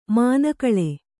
♪ māna kaḷe